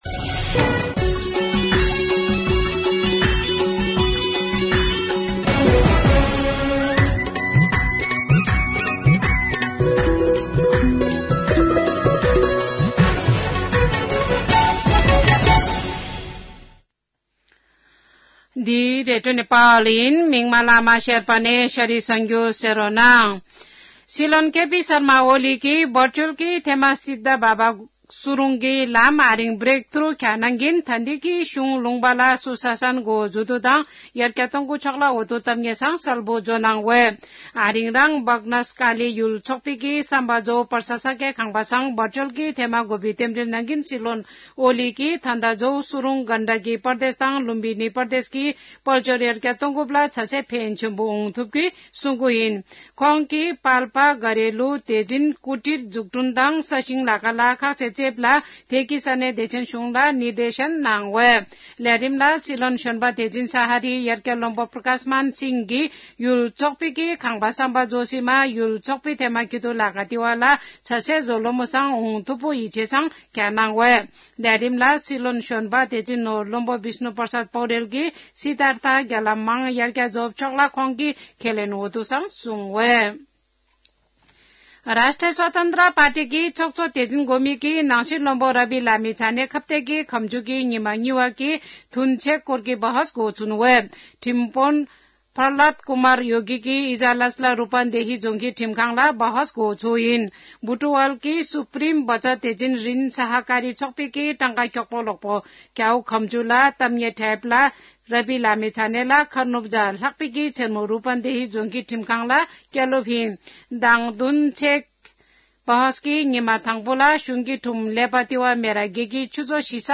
शेर्पा भाषाको समाचार : १२ माघ , २०८१
Sherpa-news-6.mp3